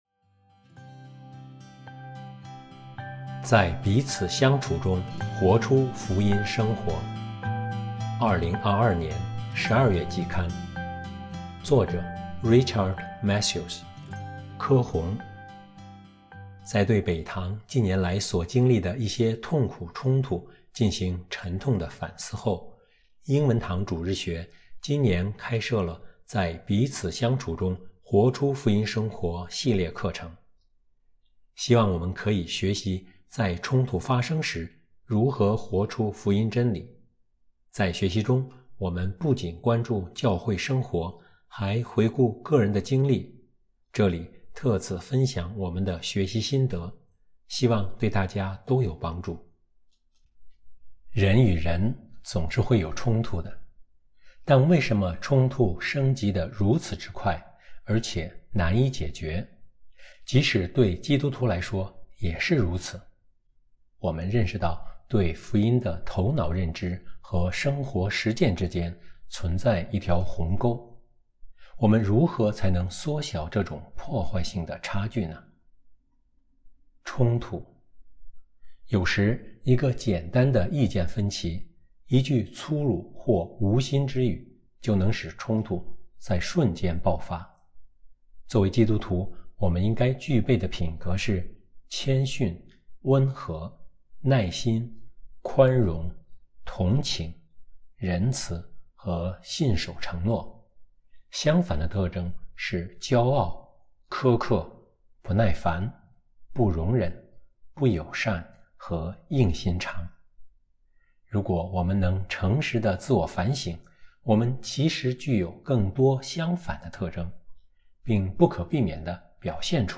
音频朗读